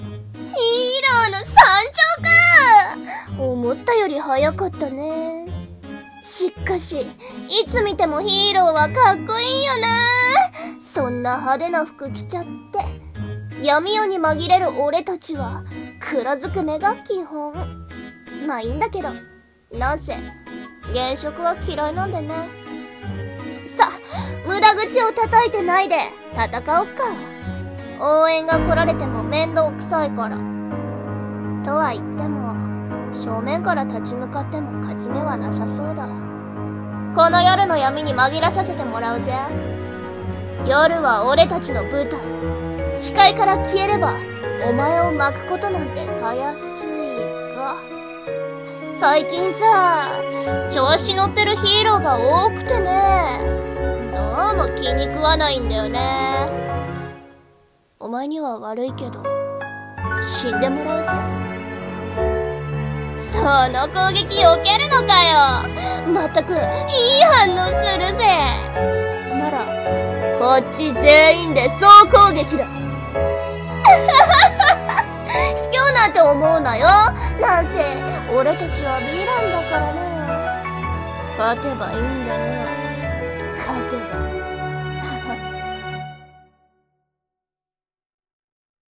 声劇【ヴィラン】※悪役声劇